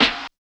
99 SNARE 4-L.wav